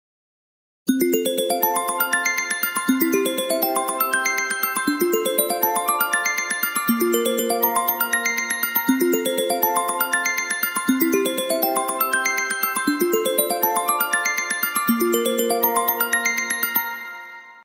Звуки будильника iPhone